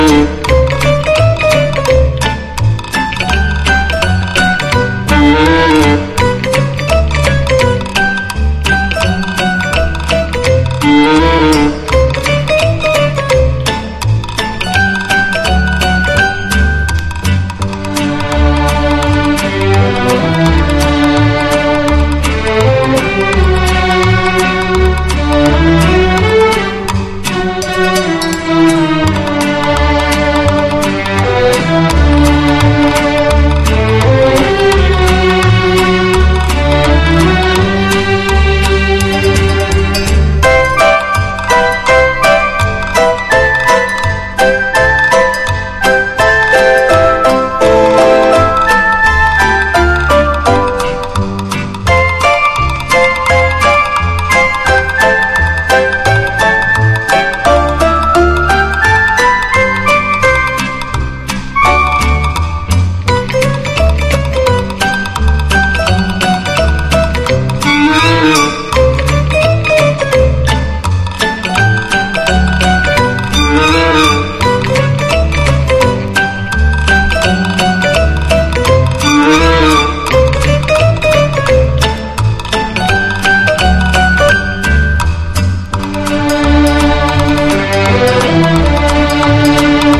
『警視ぺぺ』をはじめ美しく儚いメロの数々にウットリ。。